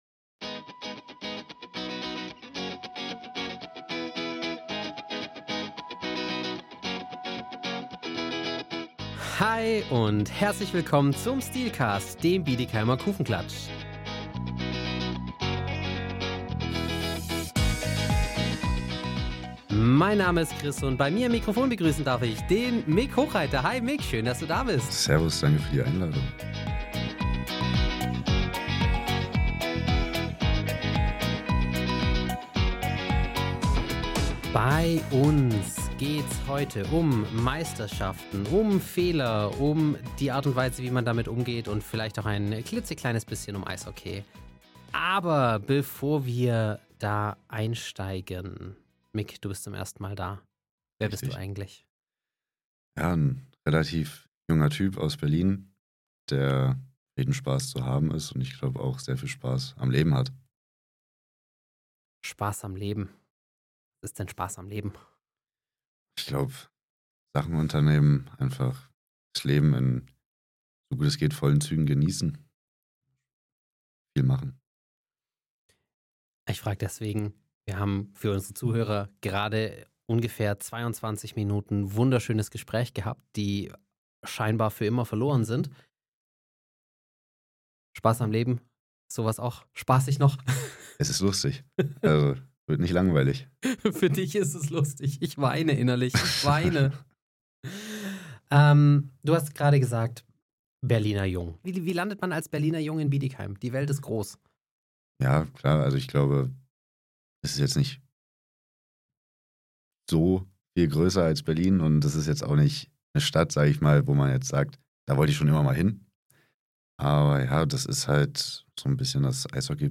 Kurzum: ein ehrliches, bodenständiges Gespräch mit viel Lachen, ein paar Brandflecken aus der Vergangenheit und einer klaren Botschaft: Verbissenheit ist gut – solange man sie mit Spaß am Leben kombiniert.